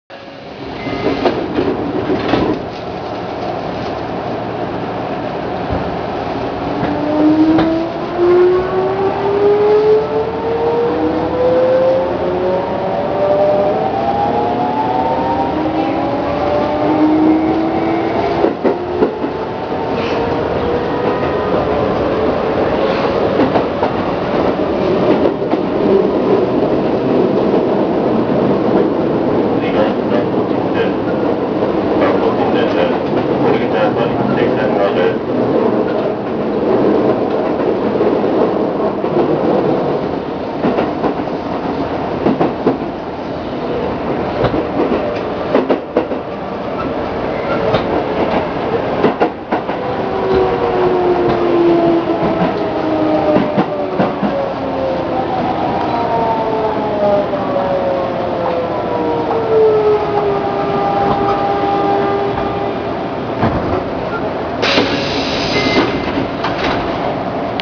・8800形(ソフト変更後)走行音
【新京成線】上本郷→松戸新田（1分7秒：368KB）
変更前と比べて若干間延びした音になりました。
モーターは三菱製。